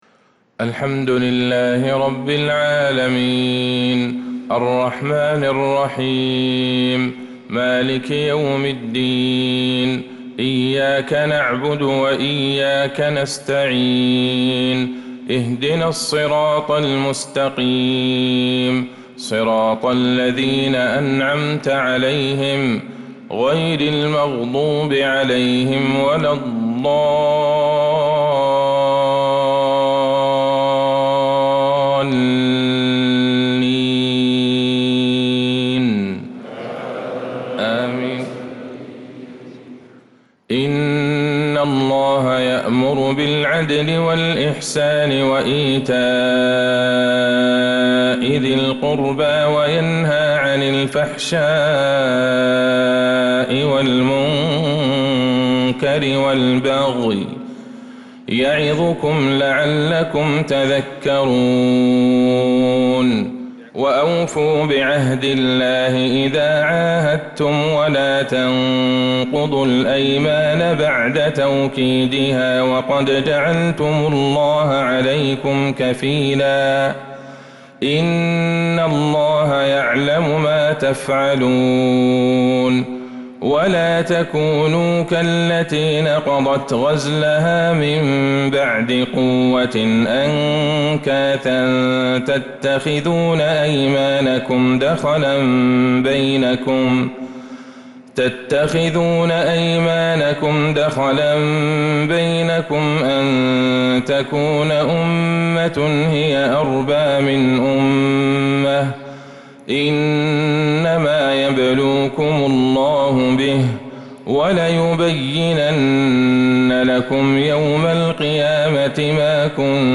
فجر الجمعة ١٣ ربيع الأول ١٤٤٧ من سورة النحل 90-102 | Fajr prayer from Surah Al-Nahl 5-9-2025 > 1447 🕌 > الفروض - تلاوات الحرمين